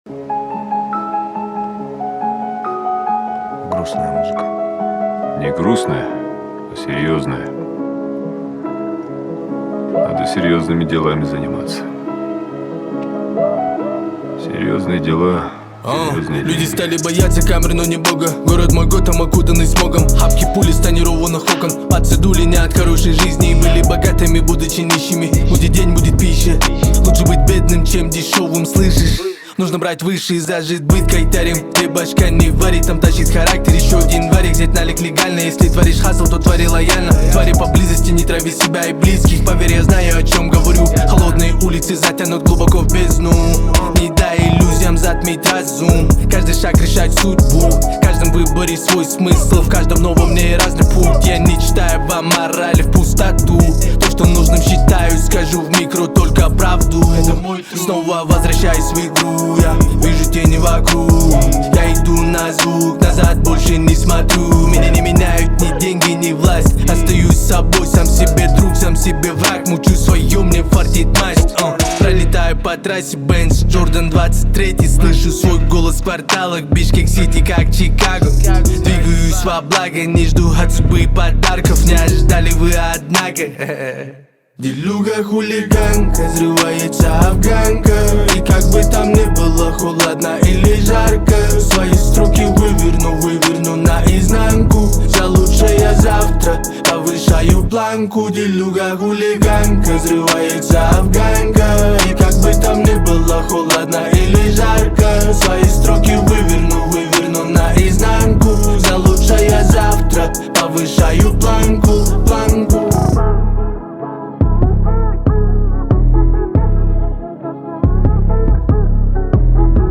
Жанр: Узбекские песни